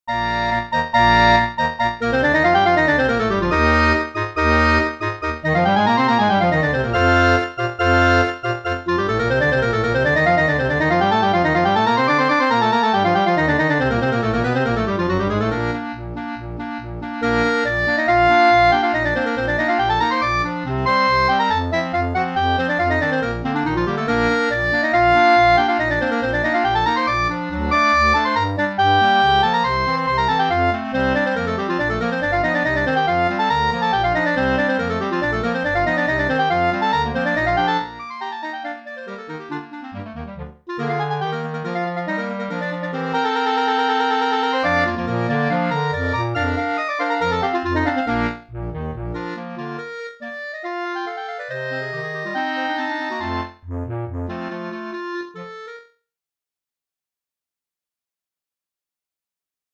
FOR CLARINET CHOIR